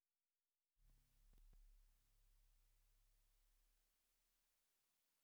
rodretract2.wav